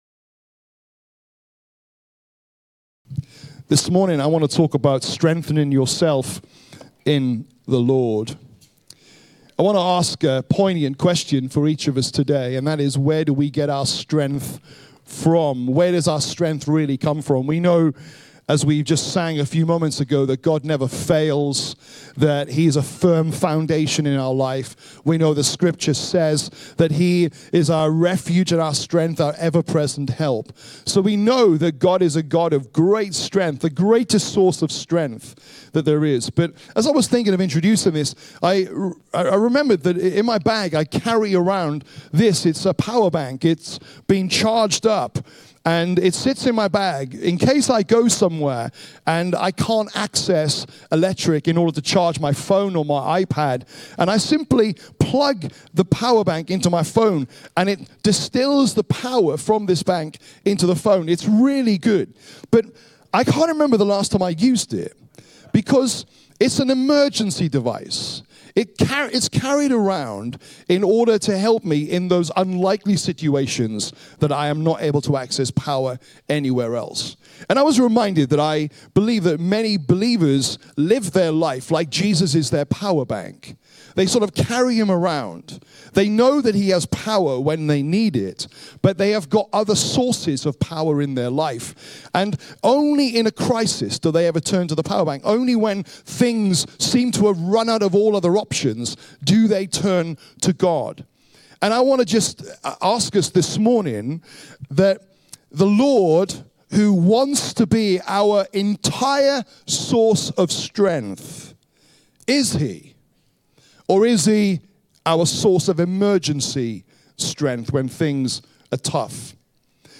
Rediscover Church Exeter | Sunday Messages